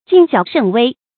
敬小慎微 注音： ㄐㄧㄥˋ ㄒㄧㄠˇ ㄕㄣˋ ㄨㄟ 讀音讀法： 意思解釋： 敬：謹慎小心；小、微：指細小、不起眼的東西。